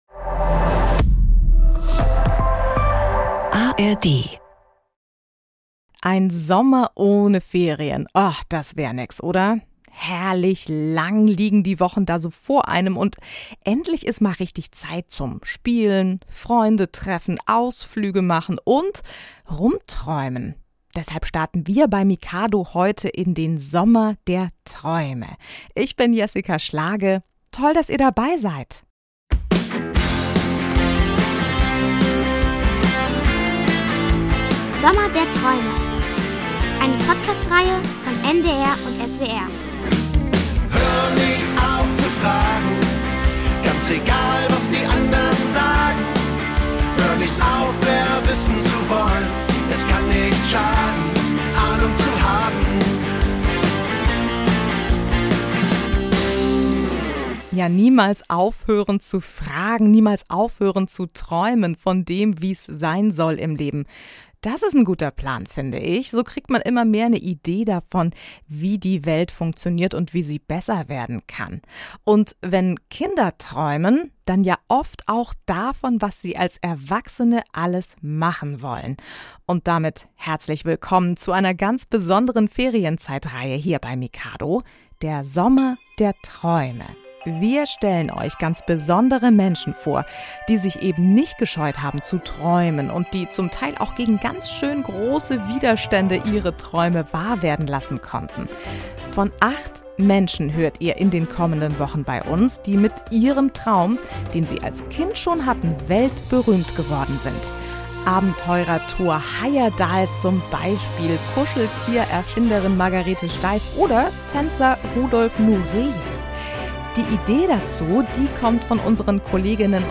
Sommer Der Träume: Affenforscherin Jane Goodall Hörspiele, Geschichten Und Märchen Für Kinder | Mikado podcast